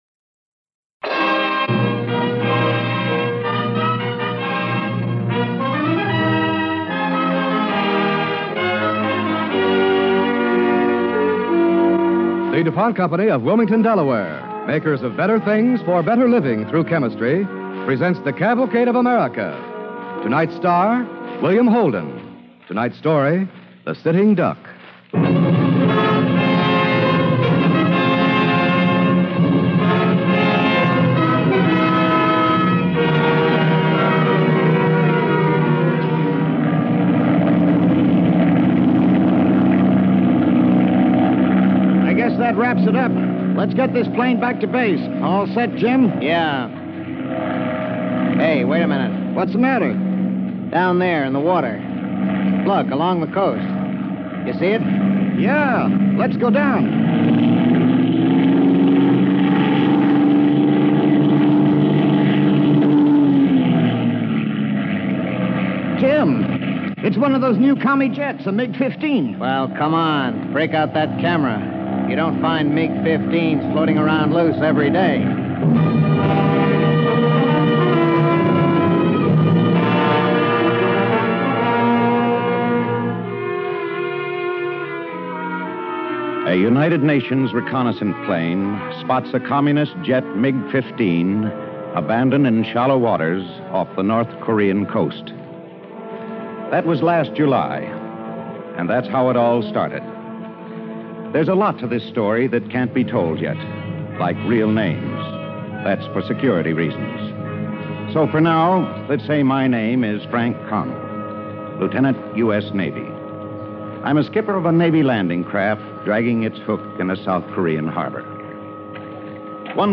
starring William Holden and Robert Dryden
Cavalcade of America Radio Program